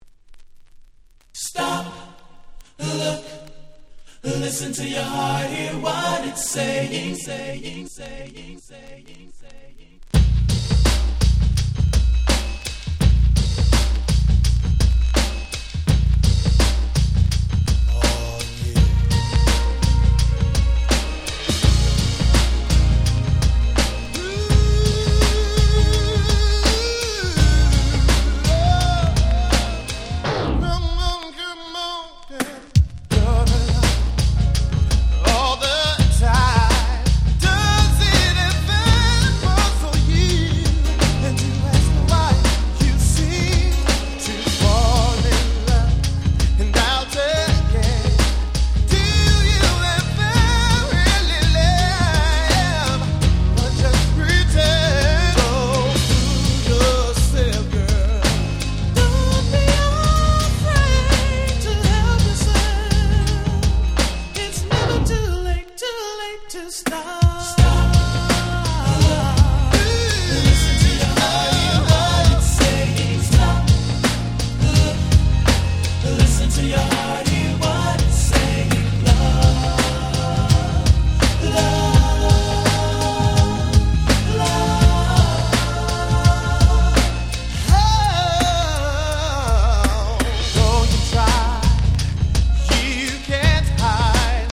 93' Very Nice R&B !!
原曲に忠実なAlbum Versionも良いですが、しっかりと90's風味に味付けされたRemixがとにかく秀逸。